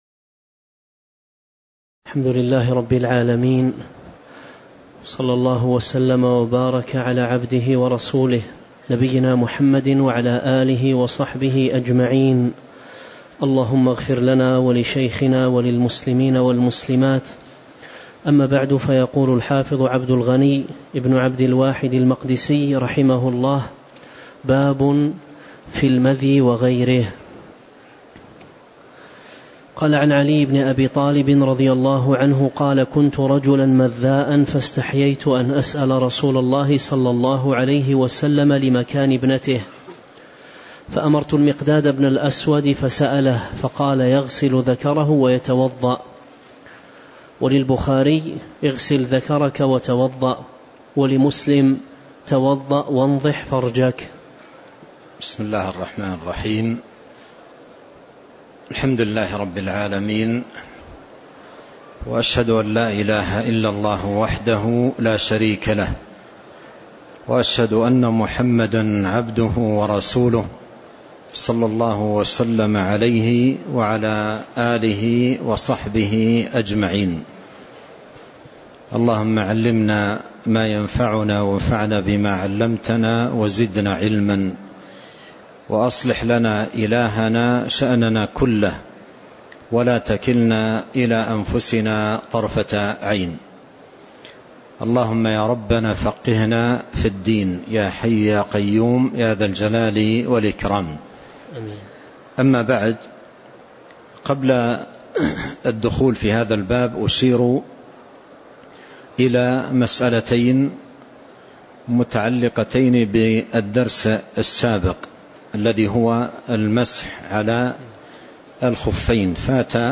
تاريخ النشر ١٣ ربيع الأول ١٤٤٤ هـ المكان: المسجد النبوي الشيخ